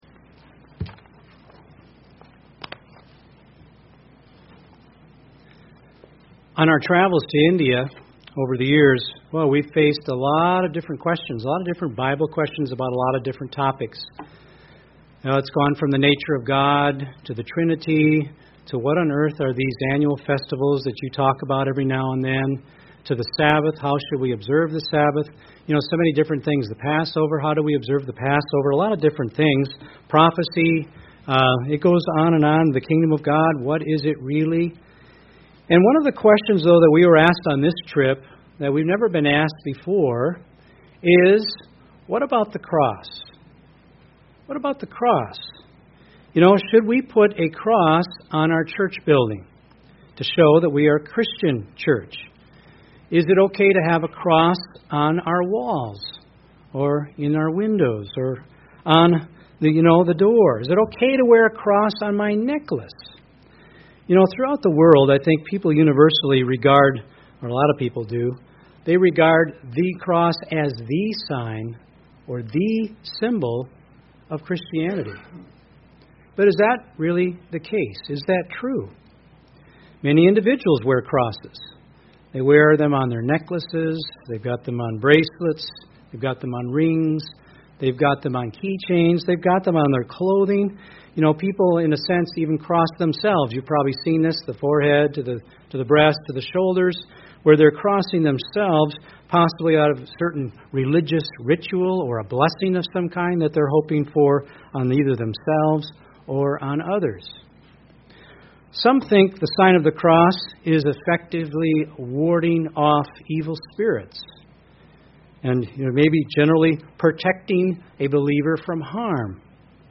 This sermon reviews this issue and refers to the scriptures and historical writing in addressing the question: Should true Christians Use the Cross in Worship?